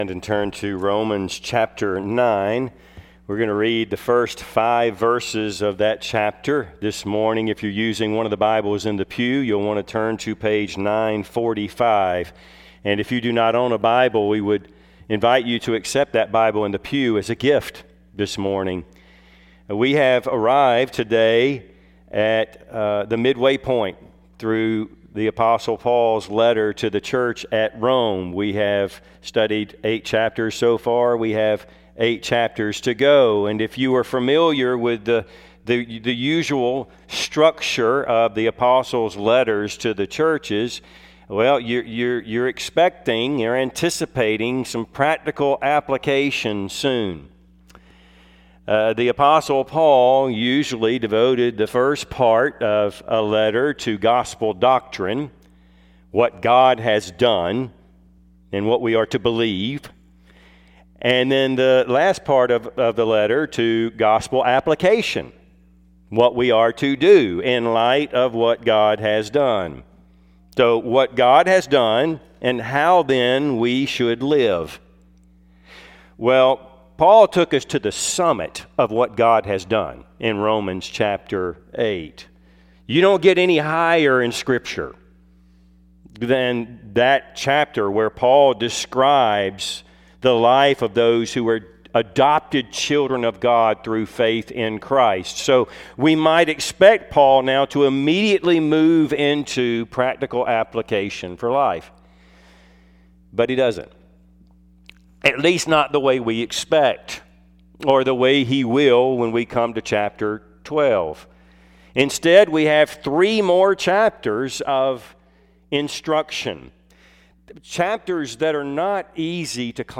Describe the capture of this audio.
Service Type: Sunday AM Topics: Christian witness , Evangelism